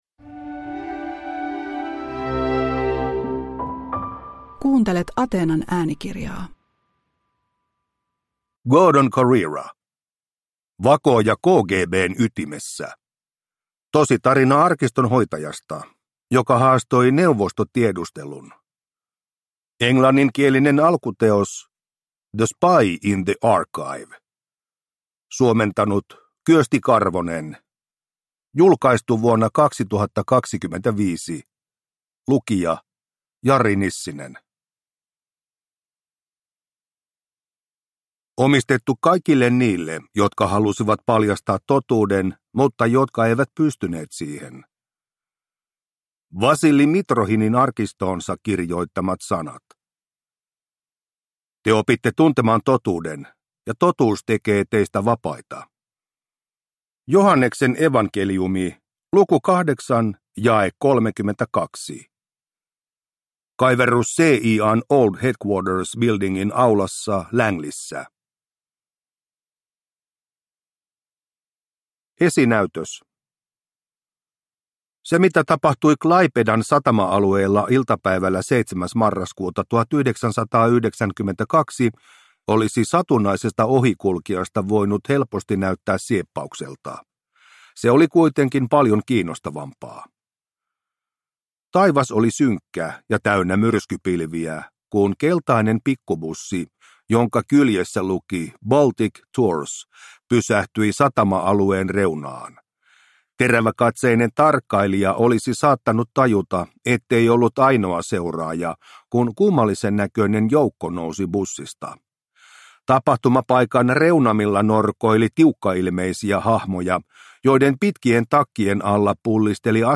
Vakooja KGB:n ytimessä – Ljudbok